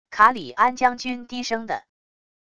卡里安将军低声的wav音频
卡里安将军低声的wav音频生成系统WAV Audio Player